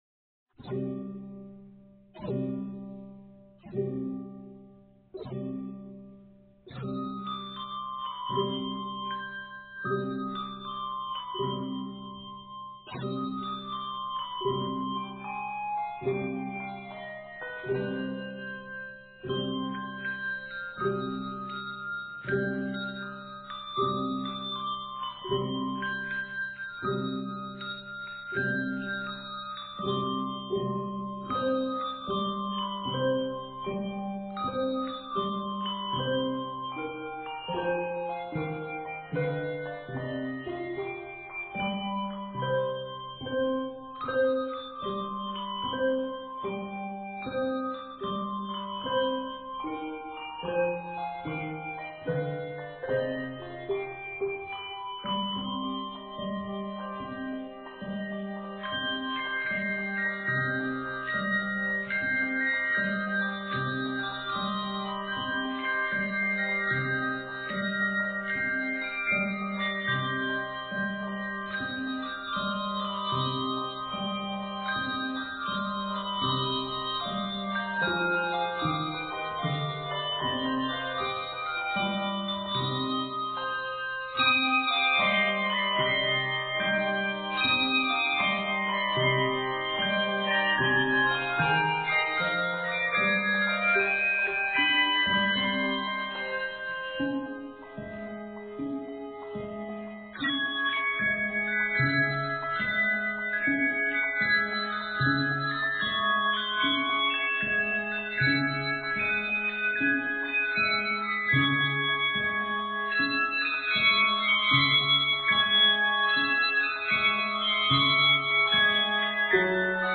full of harmonic colors